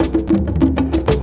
Percussion 05.wav